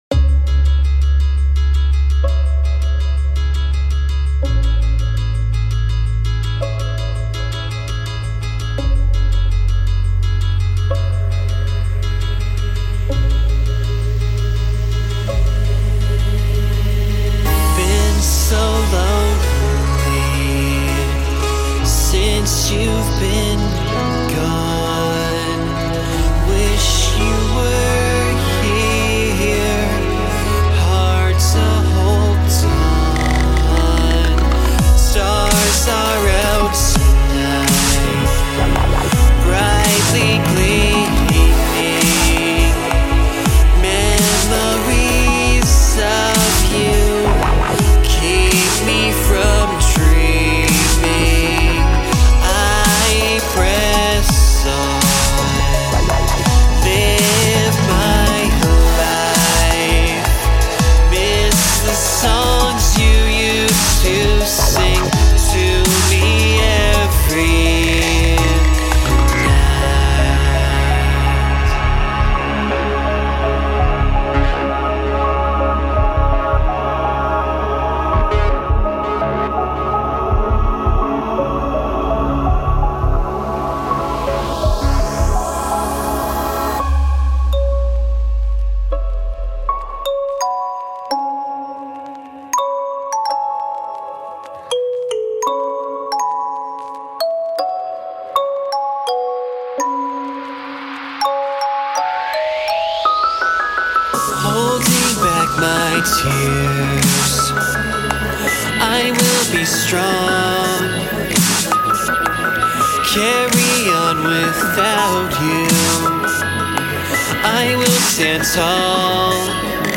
3/4 electrochillwhatever lament